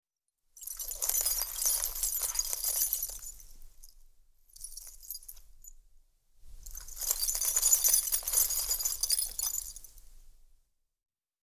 Звук бриллиантовых камней, рассыпающихся по ткани